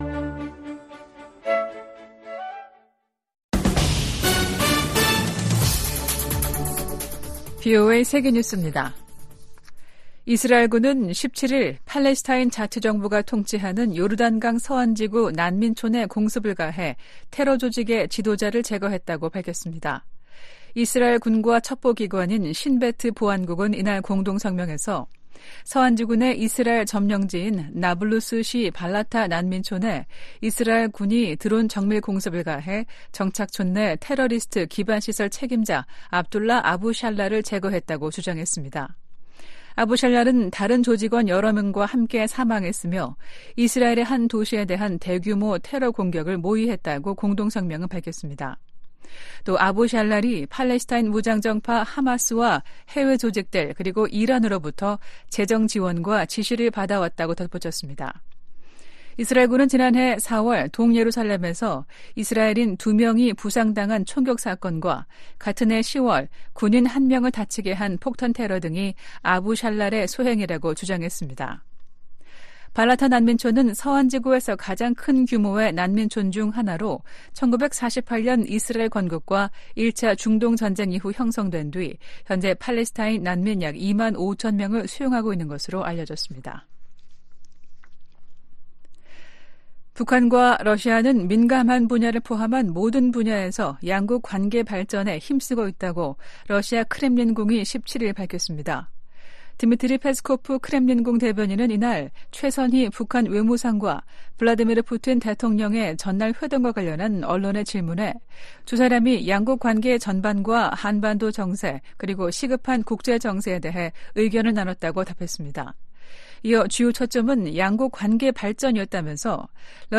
VOA 한국어 아침 뉴스 프로그램 '워싱턴 뉴스 광장' 2024년 1월 18일 방송입니다. 블라디미르 푸틴 러시아 대통령이 모스코바에서 최선희 북한 외무상을 만났습니다.